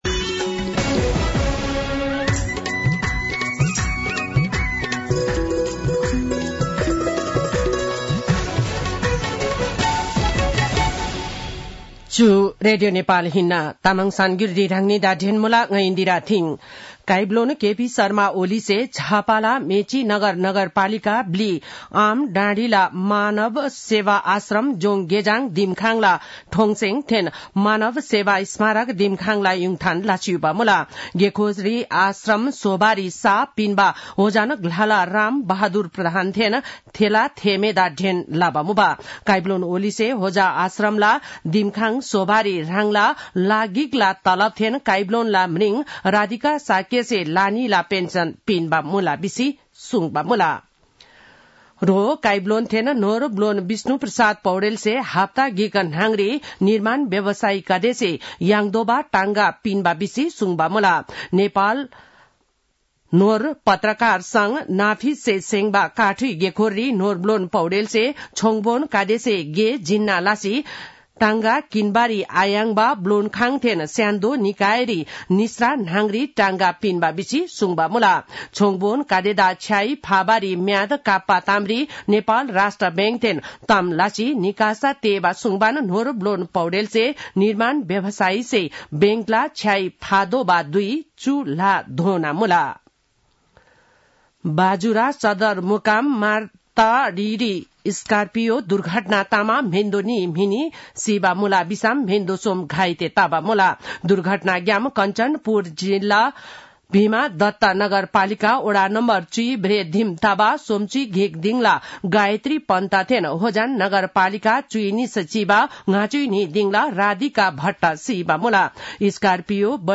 तामाङ भाषाको समाचार : २४ मंसिर , २०८१
Tamang-News-8-23.mp3